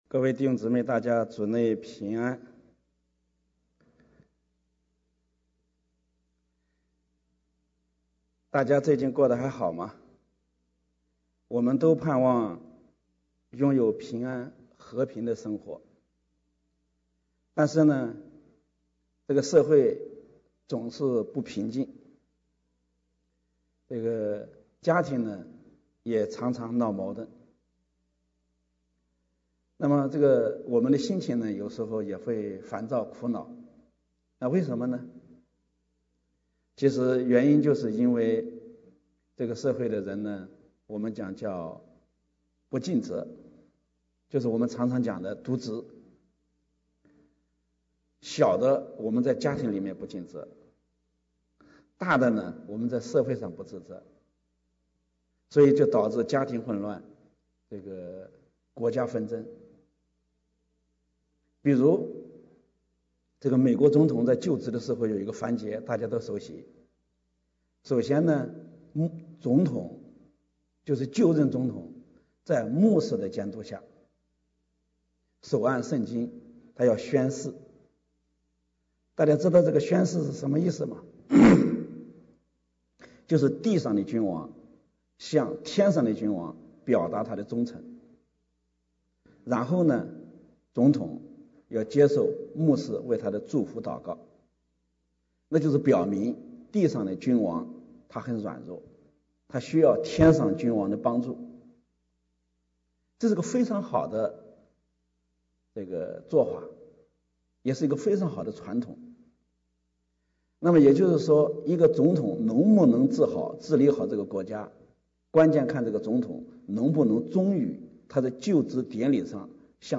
8/28/2022 国语崇拜: 「唯独耶稣基督」